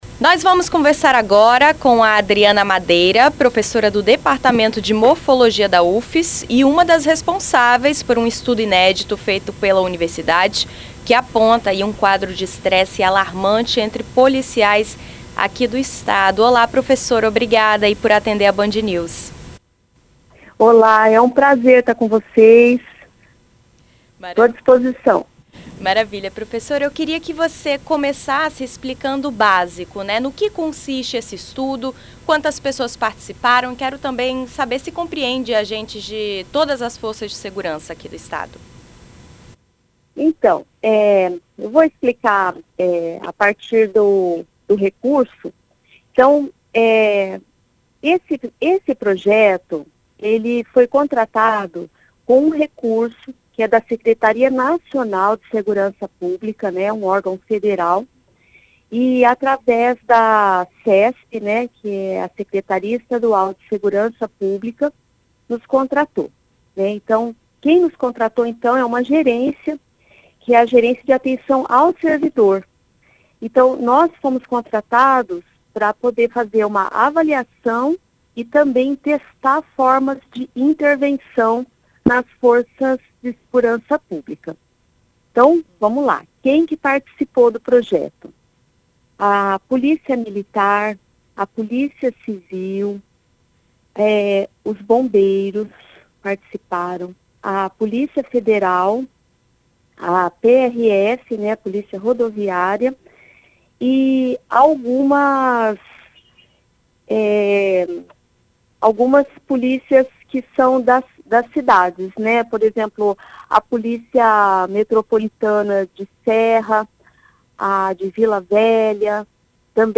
Em entrevista a BandNews FM ES